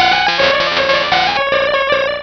Cri de Lippoutou dans Pokémon Rubis et Saphir.